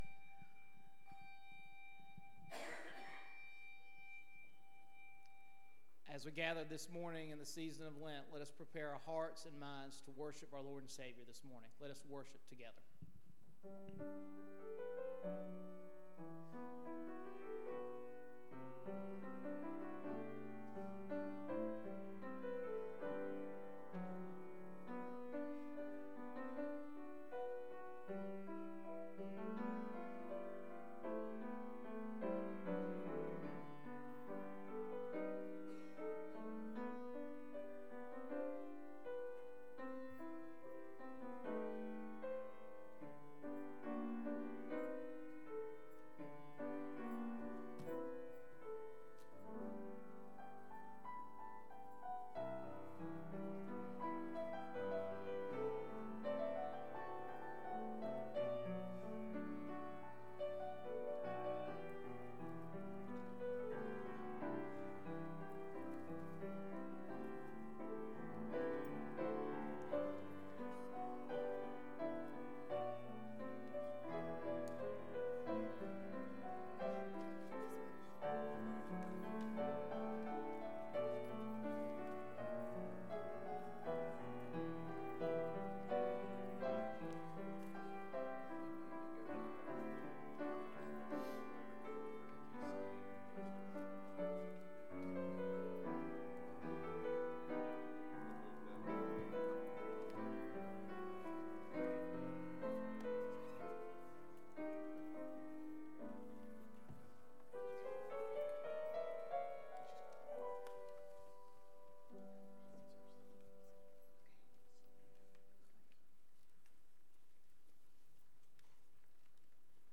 Matthew 18:21-35 Service Type: Morning Bible Text